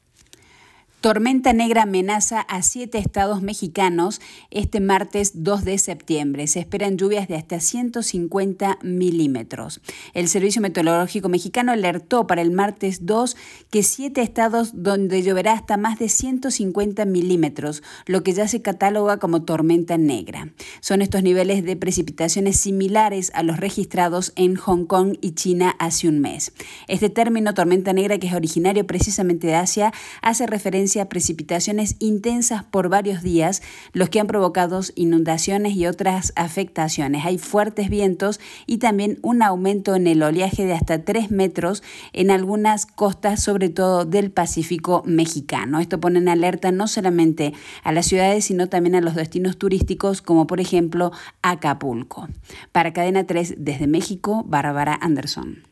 Tormenta negra amenaza a 7 estados de México: se esperan lluvias de hasta 150 mm - Boletín informativo - Cadena 3 - Cadena 3 Argentina